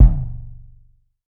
TC Kick 18.wav